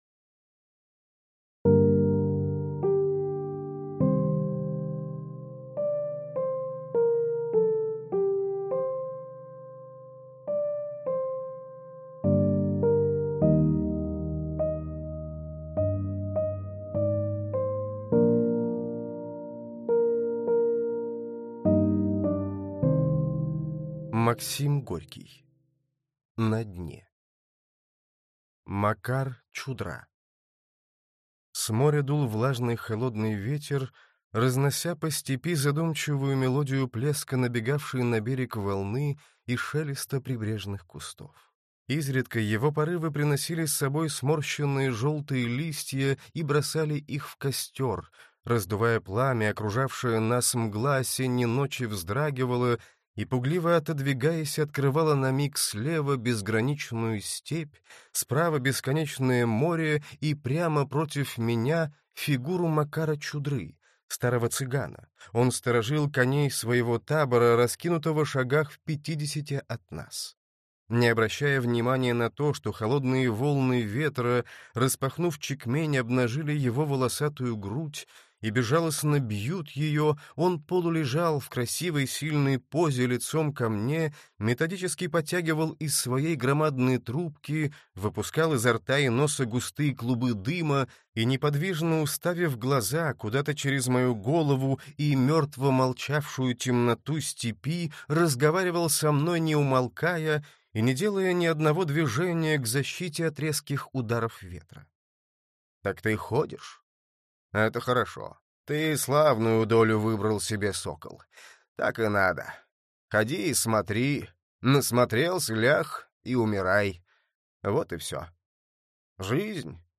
Аудиокнига На дне. Сборник | Библиотека аудиокниг